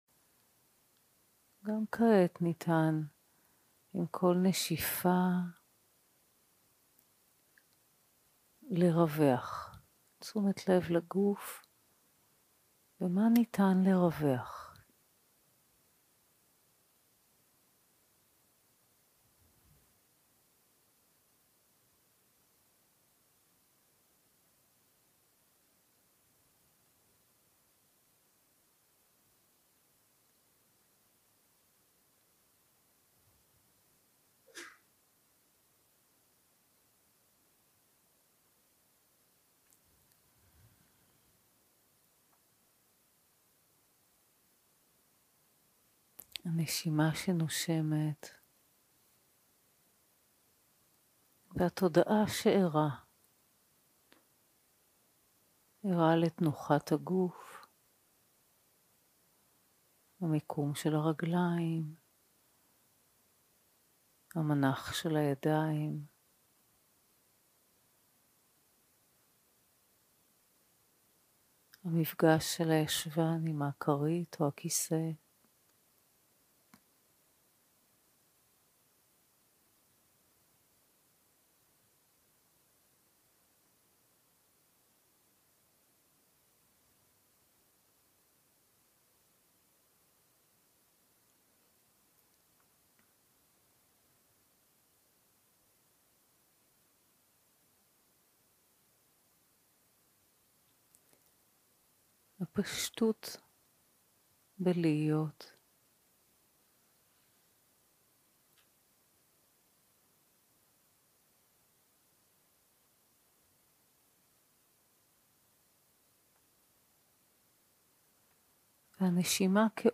יום 2 - הקלטה 4 - ערב - מדיטציה מונחית - לטפח את מיומנות התודעה
יום 2 - הקלטה 4 - ערב - מדיטציה מונחית - לטפח את מיומנות התודעה 0:00 0:00 סוג ההקלטה: Dharma type: Guided meditation שפת ההקלטה: Dharma talk language: Hebrew